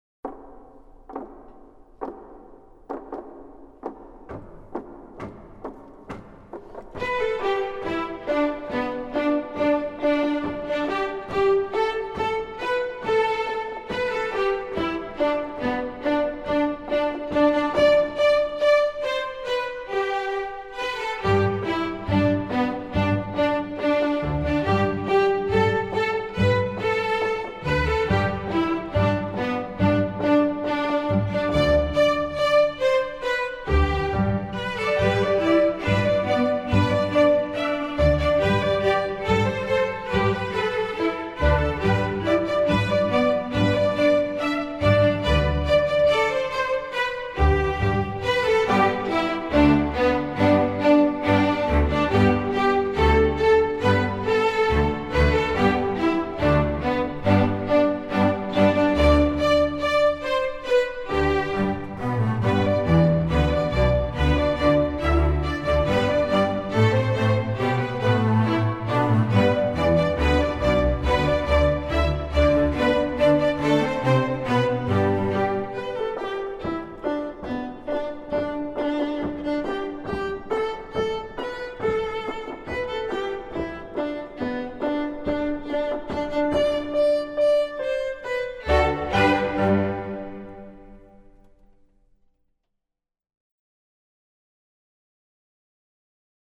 Composer: Australian Shearers'
Voicing: String Orc